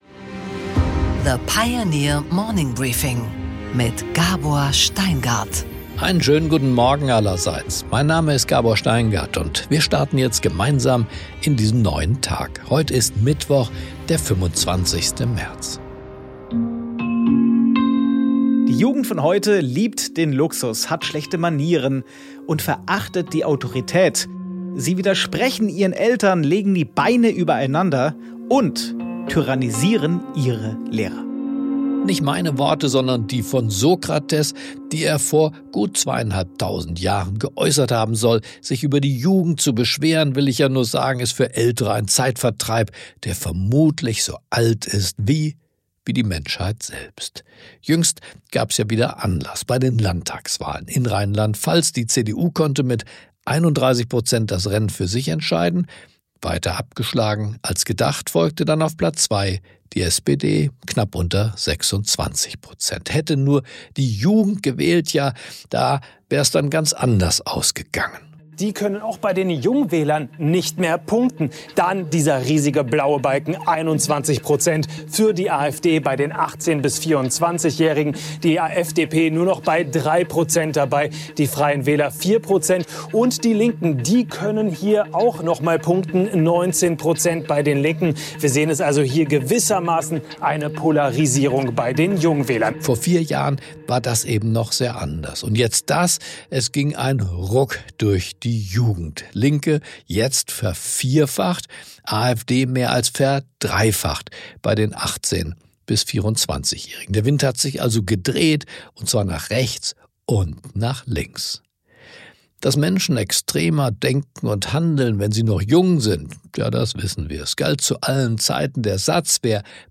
Gabor Steingart präsentiert das Morning Briefing.
Im Gespräch: Klaus von Dohnanyi, SPD-Urgestein und Bundesminister a.D., ordnet im Gespräch mit Gabor Steingart die Krise seiner Partei ein — und spart nicht mit Kritik am eigenen Lager.